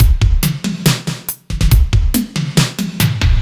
Index of /musicradar/french-house-chillout-samples/140bpm/Beats
FHC_BeatC_140-03.wav